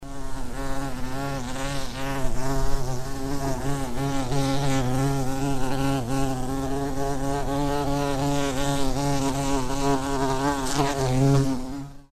На этой странице собраны натуральные звуки шмелей — от монотонного жужжания до активного полета между цветами.
Звук полета шмеля